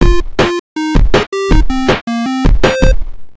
level complete victory sound